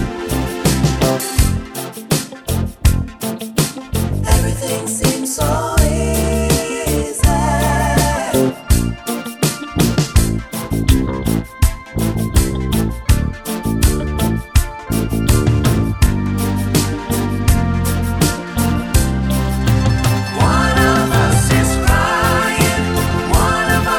Two Semitones Down Pop (1970s) 4:09 Buy £1.50